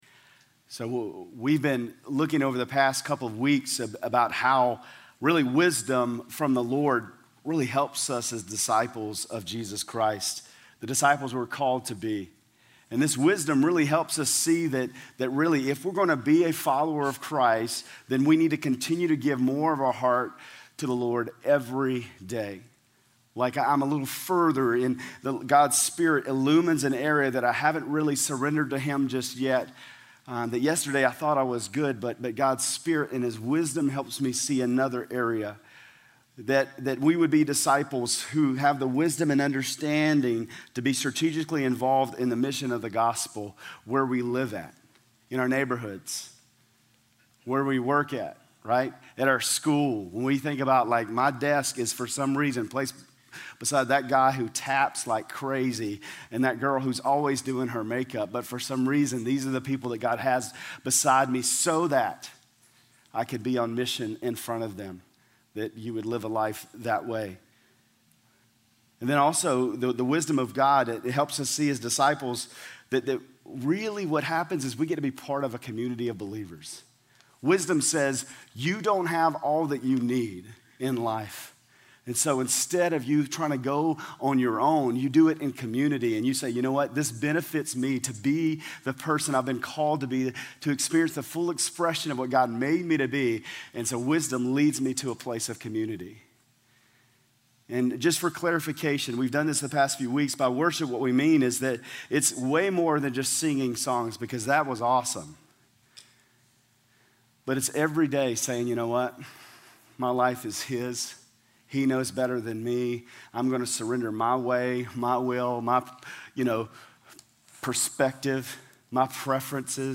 GCC-Lindale-January-16-Sermon.mp3